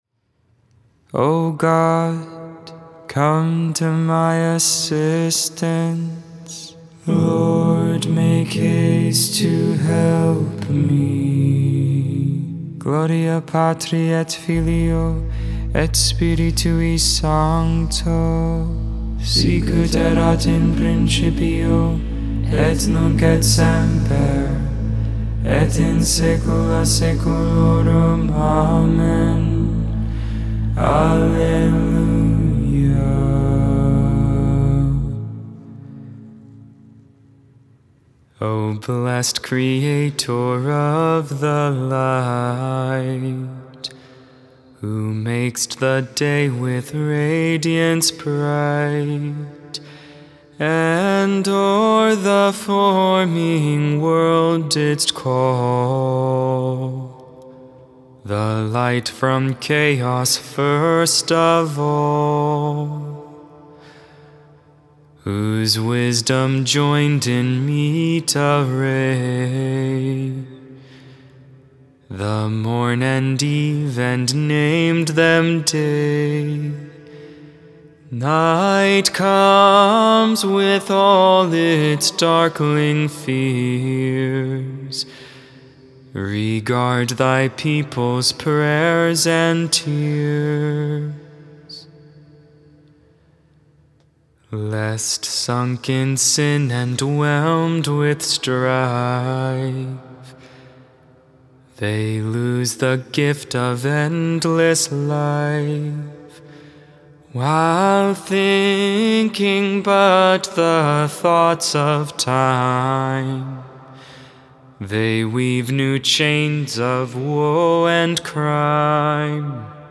Vespers II, Sunday Evening Prayer on the 7th Sunday in Ordinary Time, February 20th, 2022.